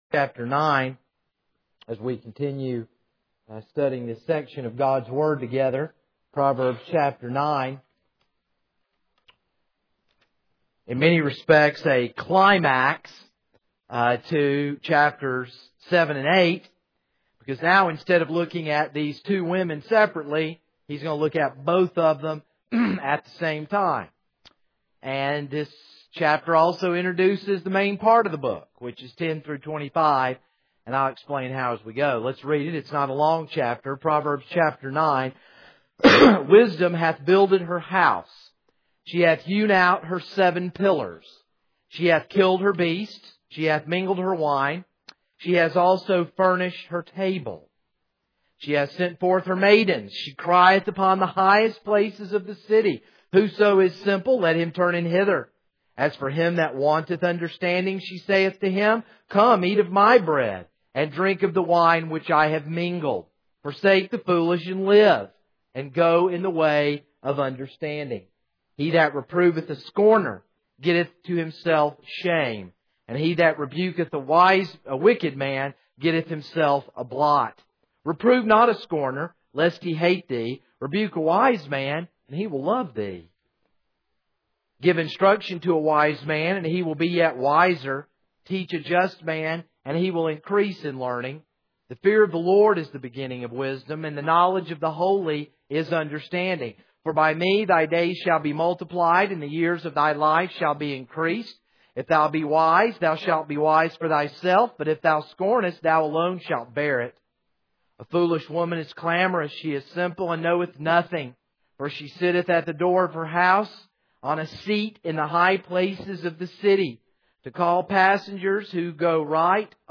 This is a sermon on Proverbs 9.